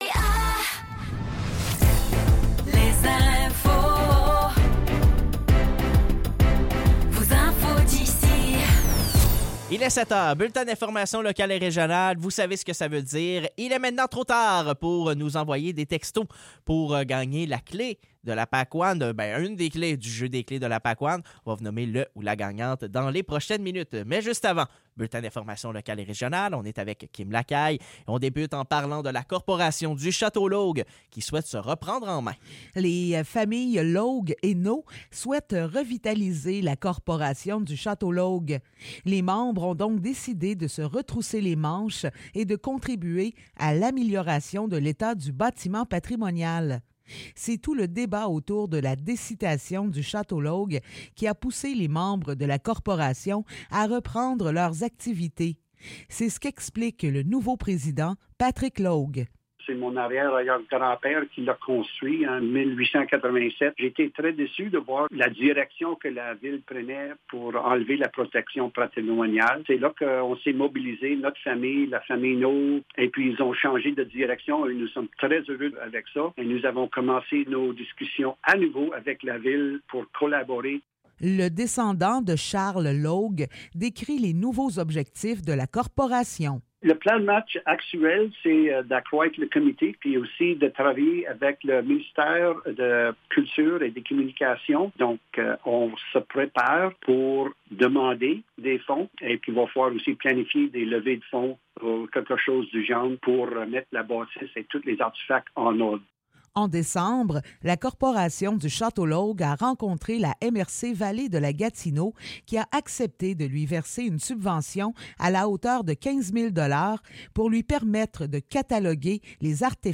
Nouvelles locales - 19 janvier 2024 - 7 h